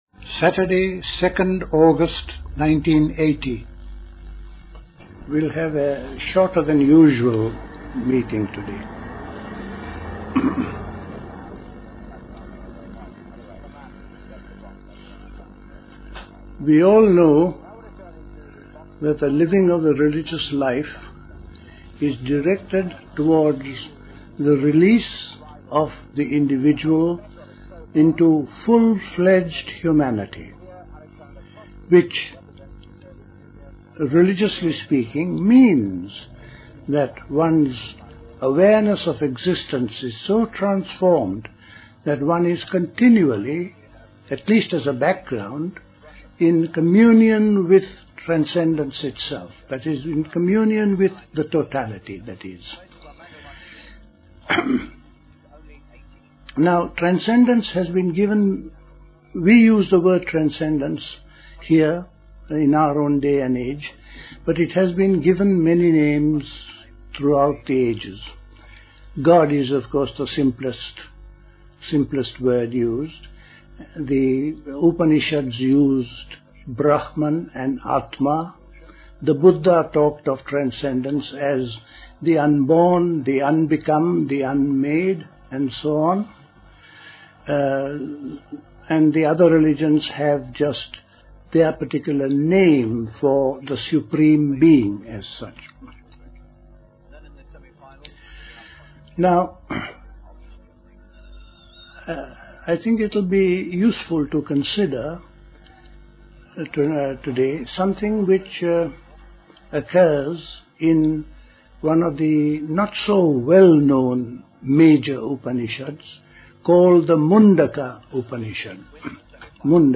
A talk
Dilkusha, Forest Hill, London